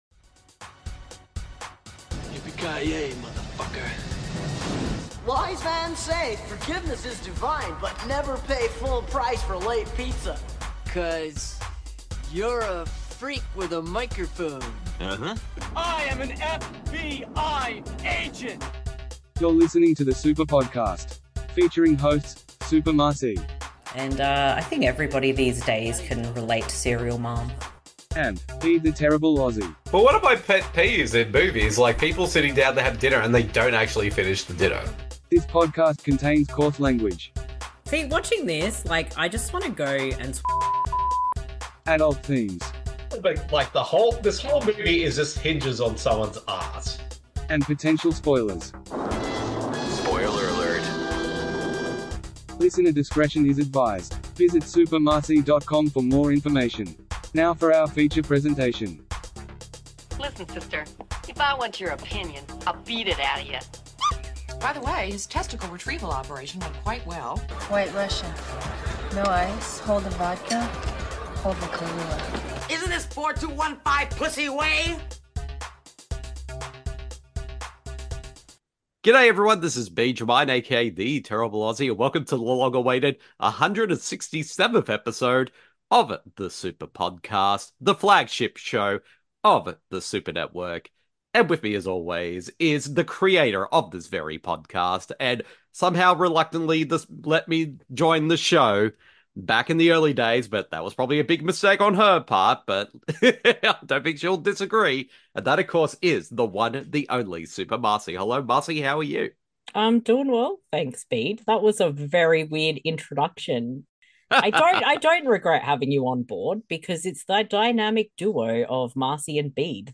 The idea is to pick a film that the person hasn’t seen before and discuss! The theme for this Movie Swap was ‘Foreign Films From The 00s’.